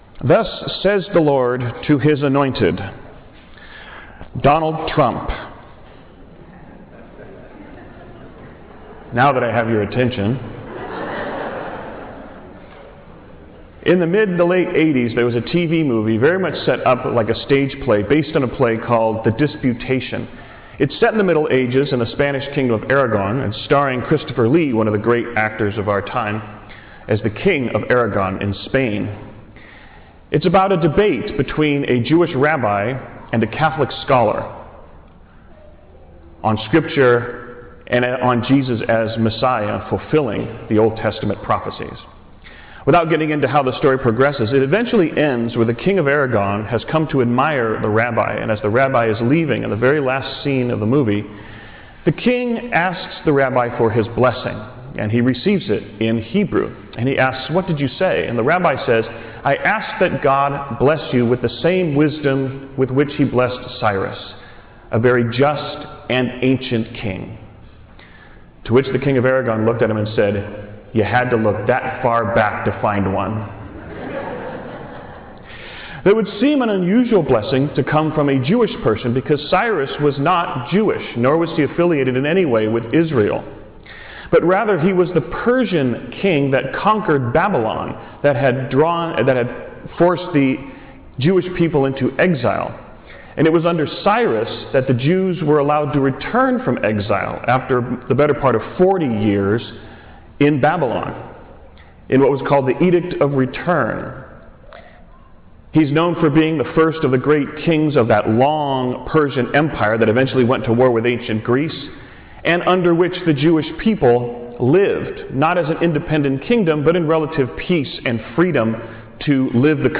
“God’s Anointed” Homily – 29th Sunday of the Year (A)